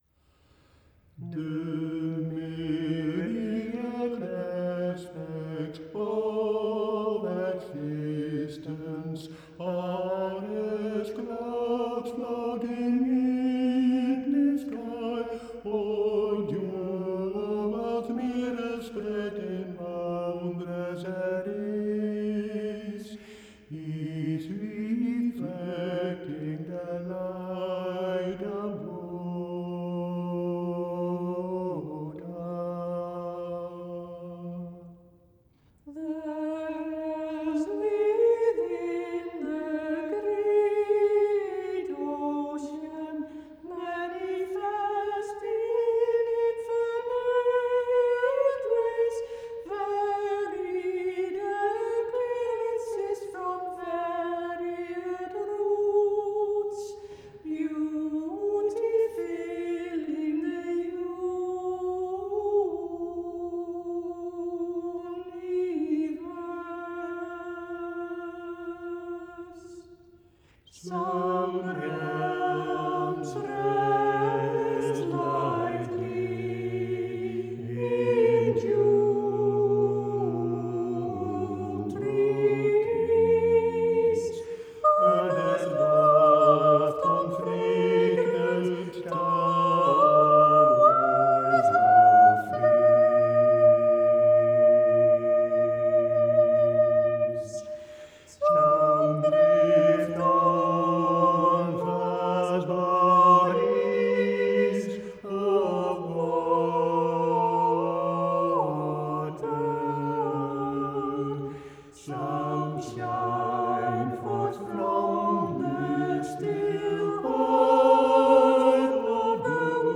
Soprano
Alto
Baritone
our organ player
Without becoming too technical, as singers we had to adapt to the many changes within this one piece: sometimes the beat of the music is in fours and sometimes in threes; there are also tempo changes and key changes; it has solo sections, contrapuntal sections, and hymn-like harmonic choral lines.
For the listener, the music just takes one on a joyful, fascinating ride through all these different planes, spheres, realms and worlds that ends with the majestic final chord which reveals the truth underlying them all: "the One in all things."